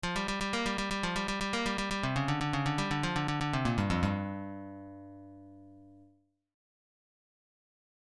Blues lick > Lick 4